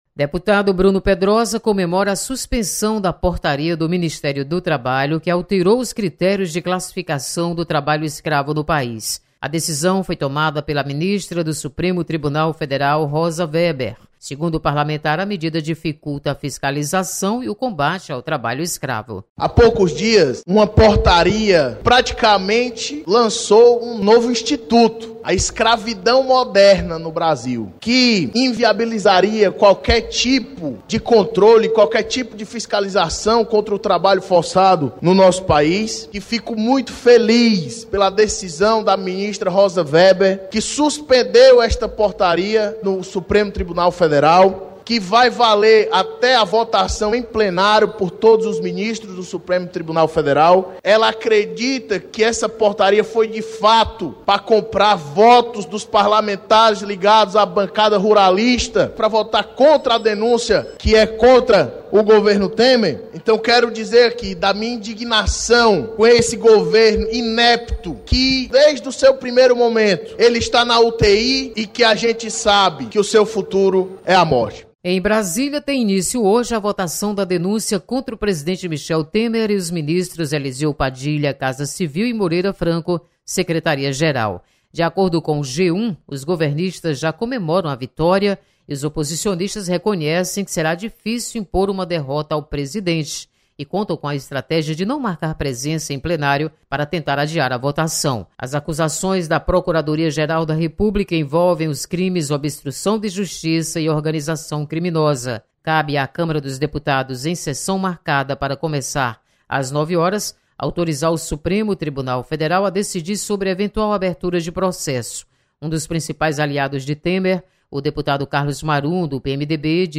Deputado lança críticas ao governo Temer. Repórter